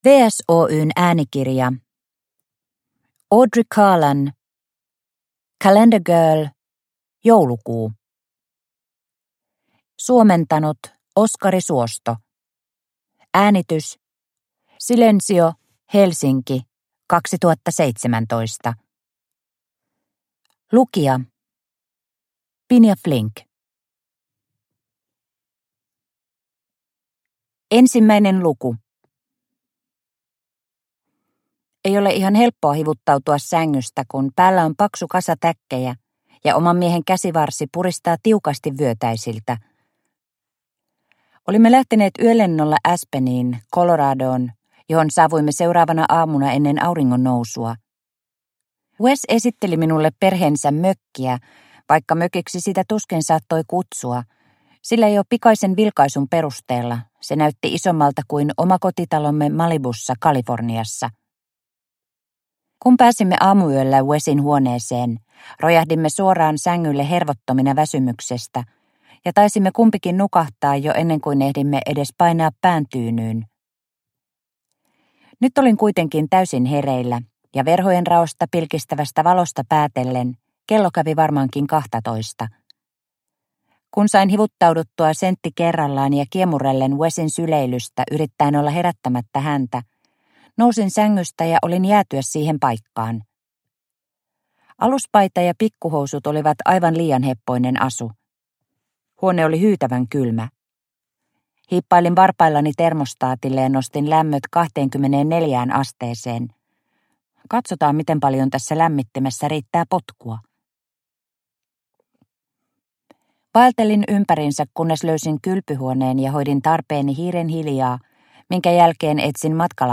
Calendar Girl. Joulukuu – Ljudbok – Laddas ner